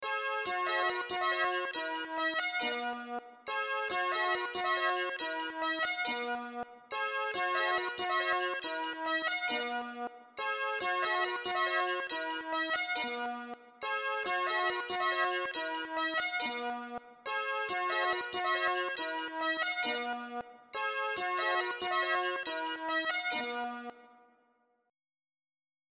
• Качество: 320, Stereo
remix
без слов
инструментальные